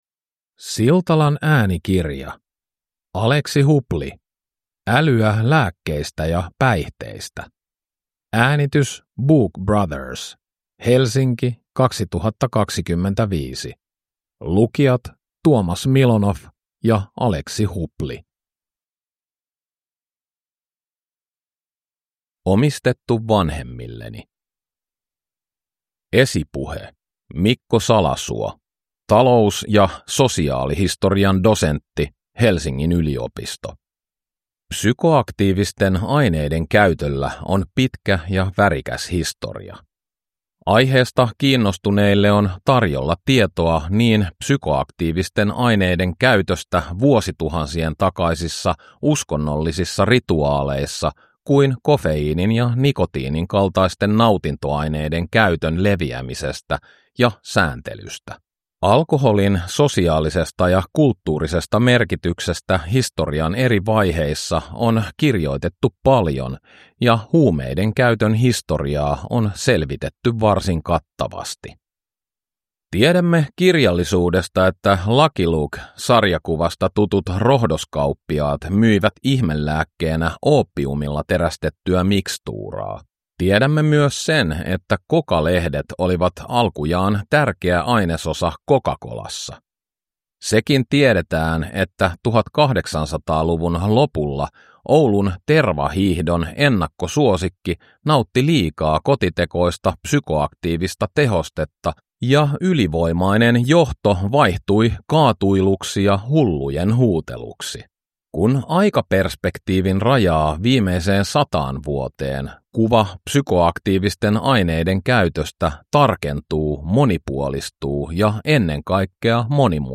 – Ljudbok